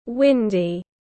Trời nhiều gió tiếng anh gọi là windy, phiên âm tiếng anh đọc là /ˈwɪn.di/.
Windy /ˈwɪn.di/
Windy.mp3